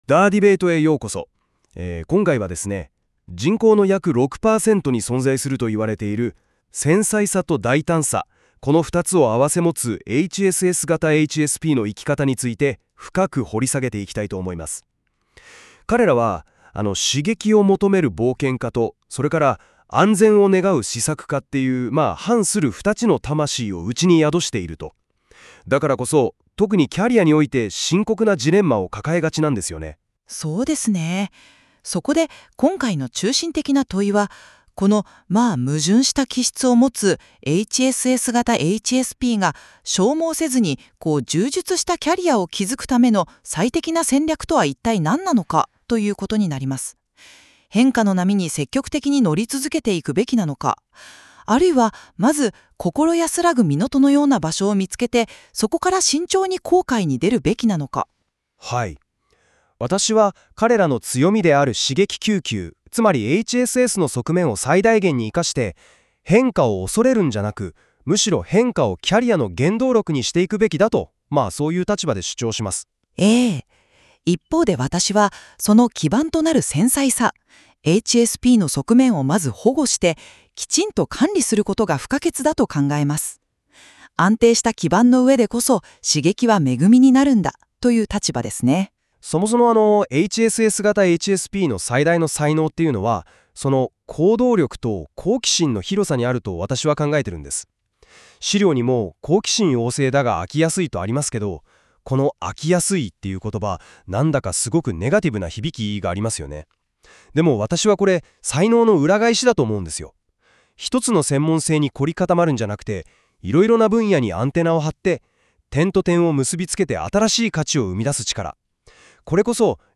【音声解説】HSS型HSPのキャリア戦略。安定か挑戦か 音声解説を追加しました。いかにしてこの気質を利用して仕事に繋げるか。